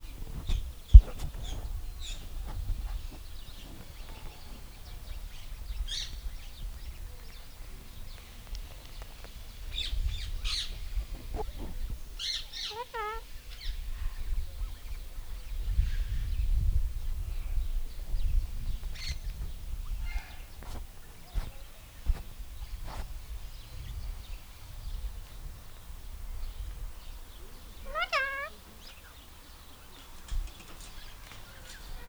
csoretmegsimogatvaketszermegszolal00.32.wav